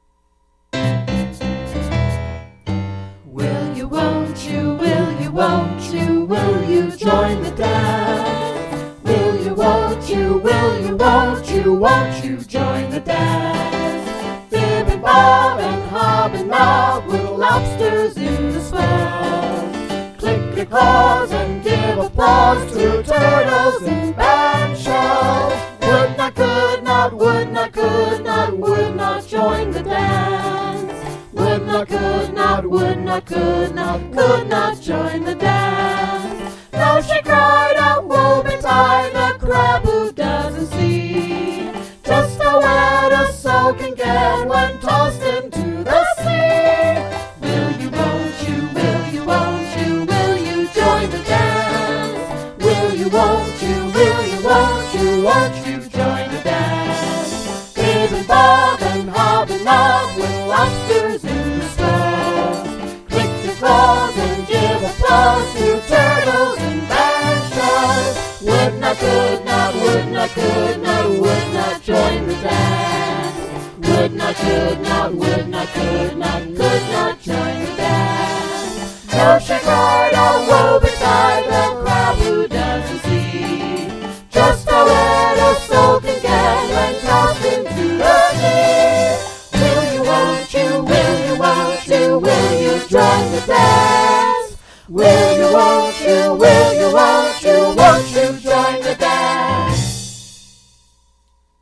Yes, it’s a novelty dance, like the Turkey Trot, or the Chicken Dance, or the Macarena, the Mashed Potato and Gangnam Style.
The Lobster Charleston is a more happy tune of nonsense, befitting the happy-go-lucky ethos of Roaring Twenties Speakeasy culture.